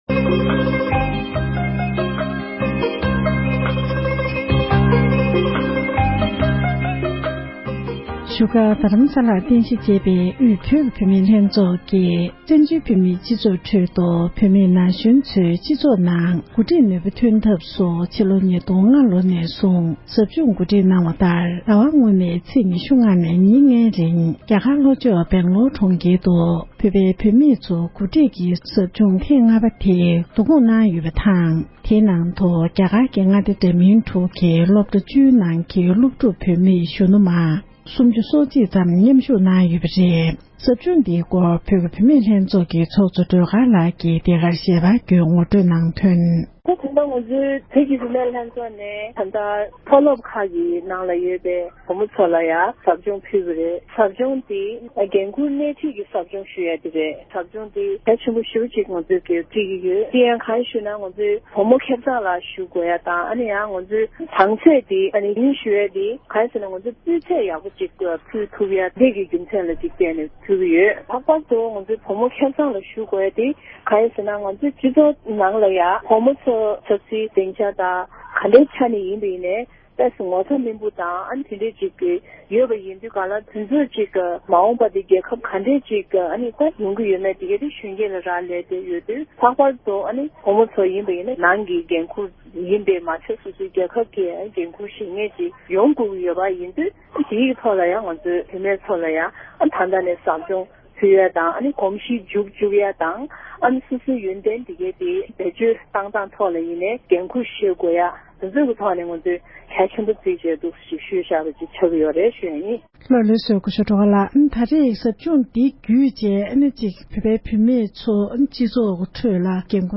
འབྲེལ་ཡོད་མི་སྣར་གནས་འདྲི་ཞུས་པ་ཞིག་གསན་རོགས༎